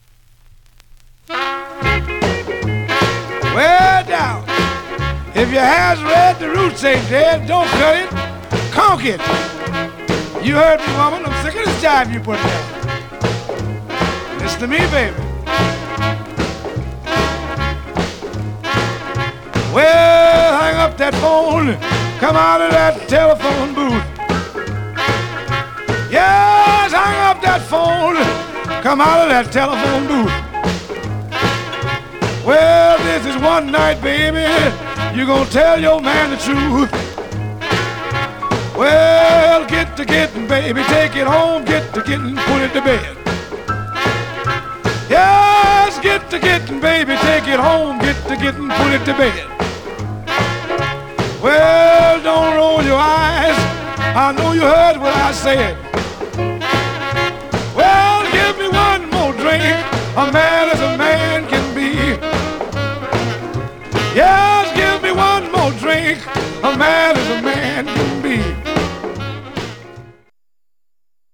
Stereo/mono Mono
Rythm and Blues